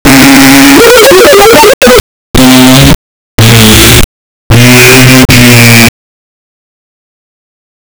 Royalty-free body sound effects
piss-poo-ryf2uzfn.wav